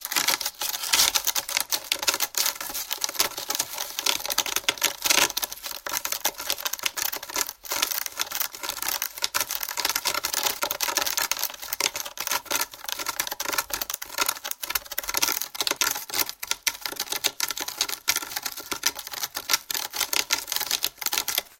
Звуки котов
кот мяукает 66